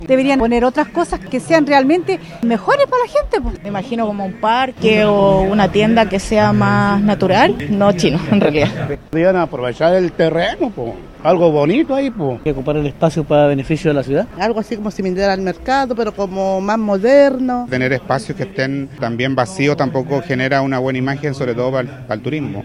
Tras ser consultados valdivianos y turistas por Radio Bío Bío, estos manifestaron en su mayoría que para ese lugar no quieren un recinto de esas características, sino que esperan que se haga cumplir la ley, pero además que tras ello el sitio no quede abandonado.